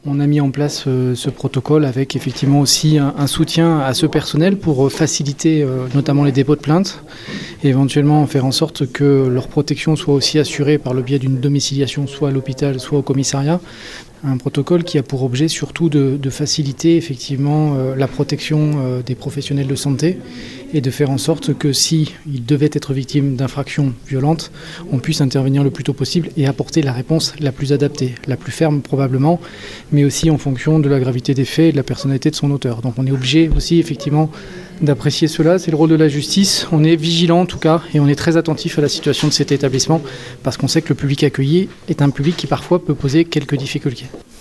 Ce protocole de sécurité va permettre aussi aux personnels de santé d’être pris en charge plus rapidement par la justice. Xavier Sicot procureur de Mende